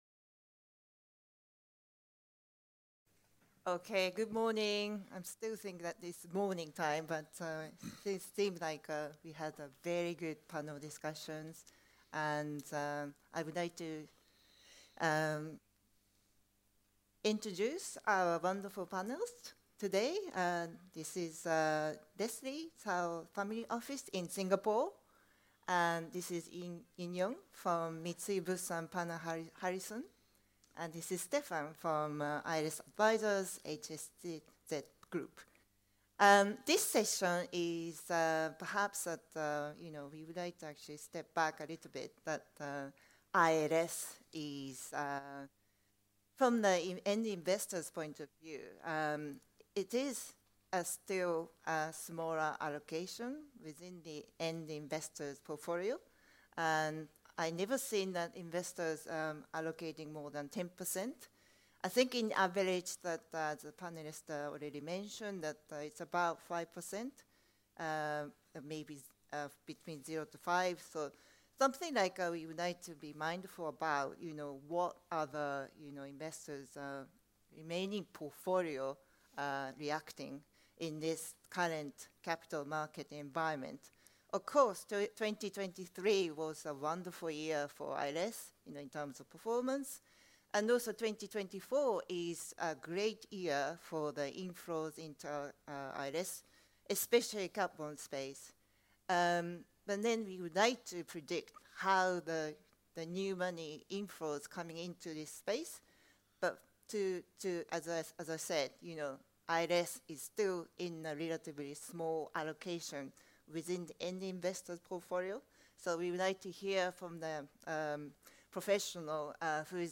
This episode features a panel session discussing investor sentiment for insurance-linked securities (ILS) and catastrophe bonds in Asia Pacific, recorded at our Artemis' ILS Asia 2024 conference, which was held in Singapore on July 11th.